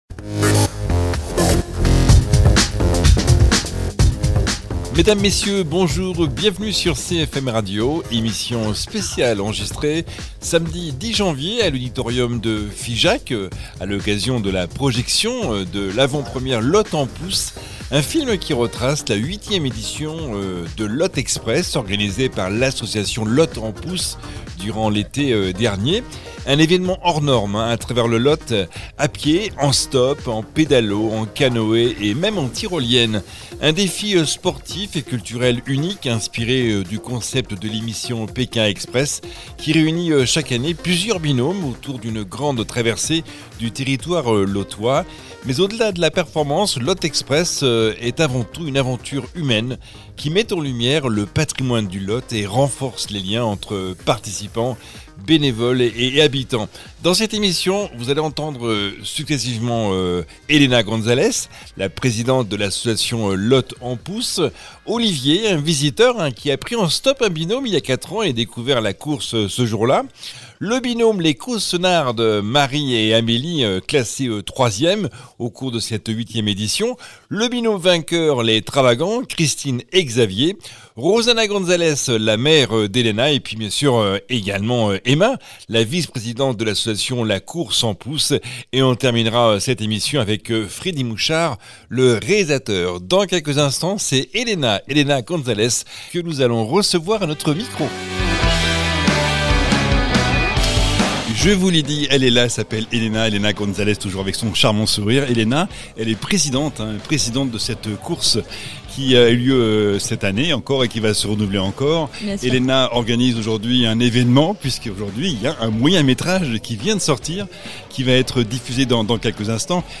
Émission spéciale enregistrée samedi 10 janvier à l’auditorium de Figeac, à l’occasion de la projection en avant-première de « Lot en Pouce », un film qui retrace la 8e édition de Lot Express, organisée par l’association La course en pouce durant l’été 2025.